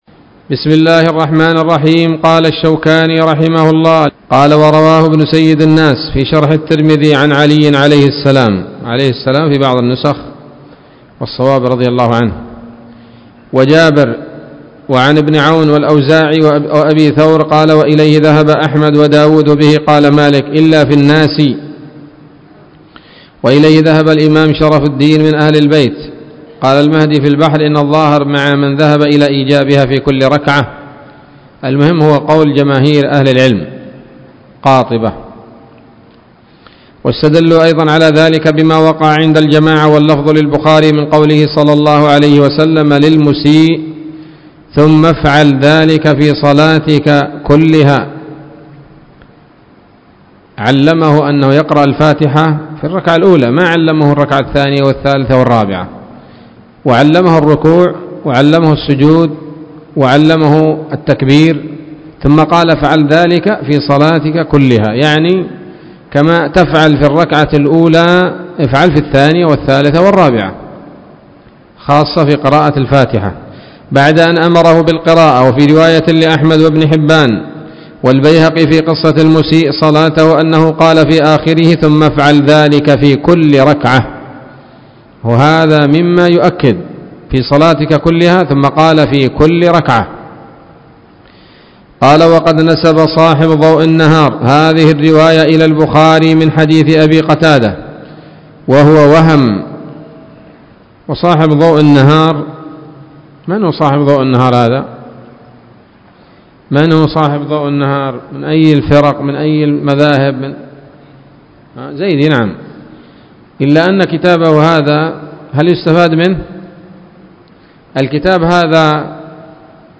الدرس الثلاثون من أبواب صفة الصلاة من نيل الأوطار